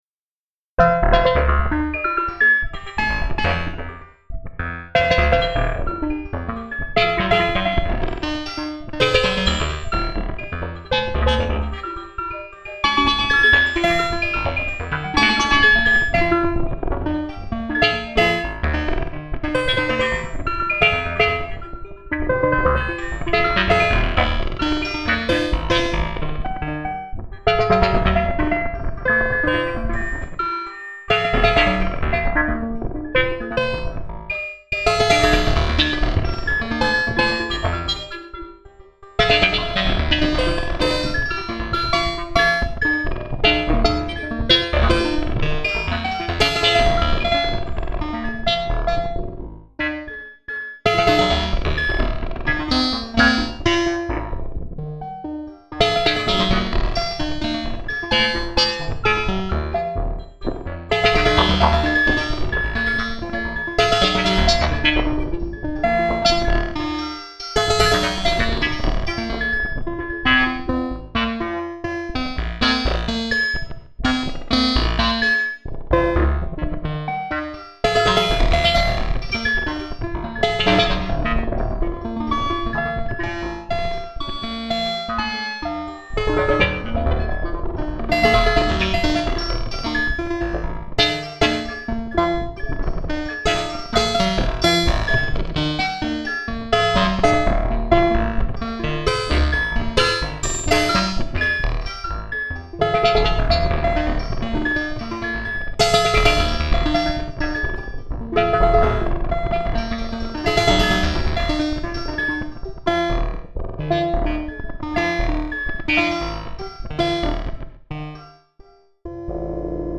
Solo Improvisations